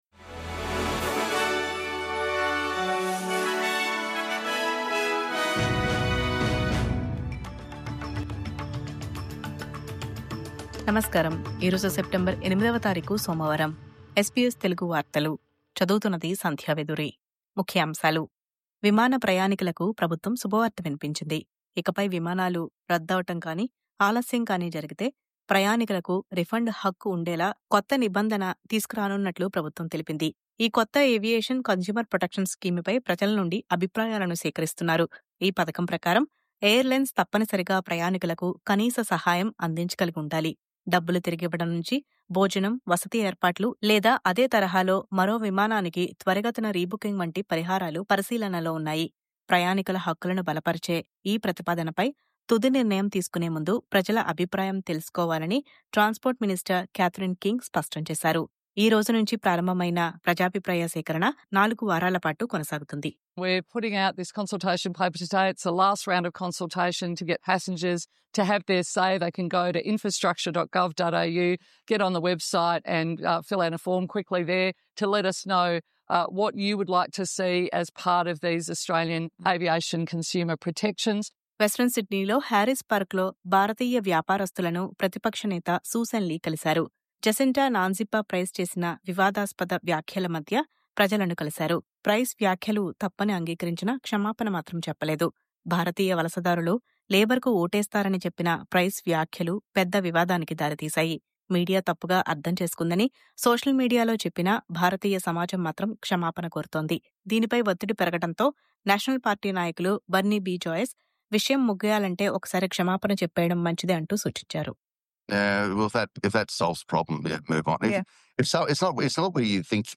News update: విమాన సేవల ఇక్కట్లకు ఇక స్వస్తి..